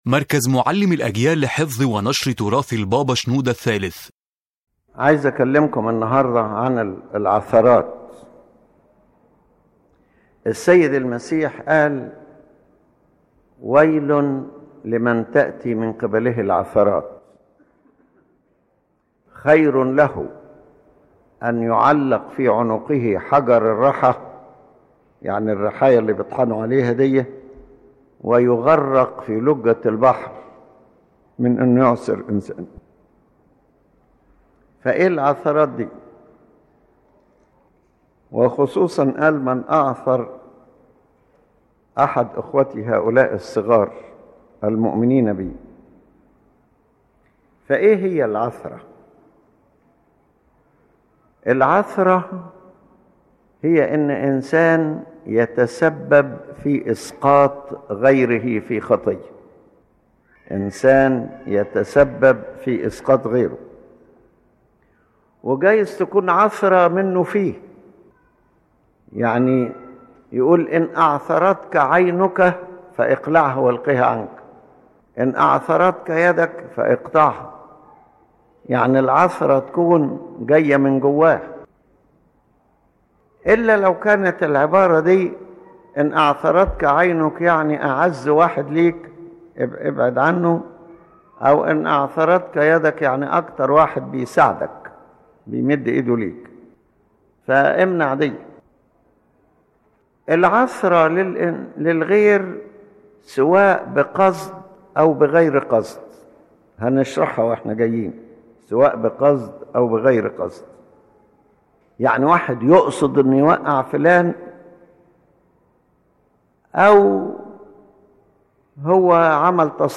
The Main Idea of the Lecture